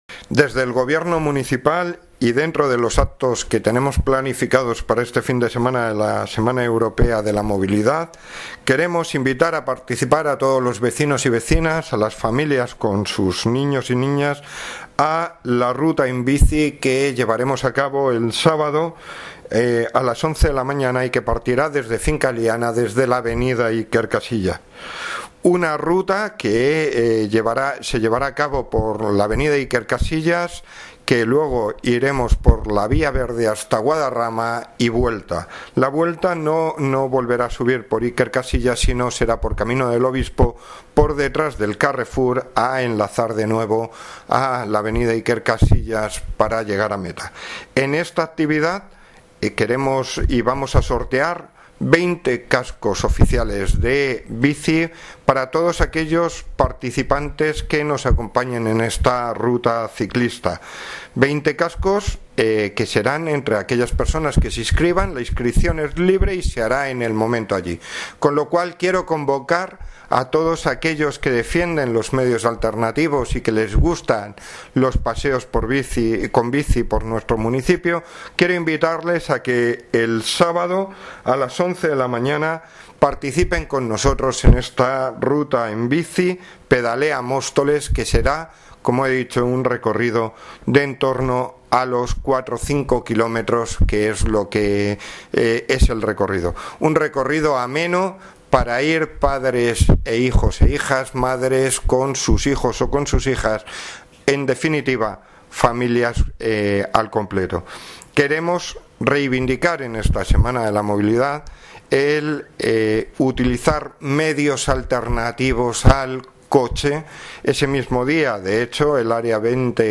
Audio - Francisco Javier Gómez (Concejal de Hacienda, Transporte y Movilidad) Sobre Ruta en bici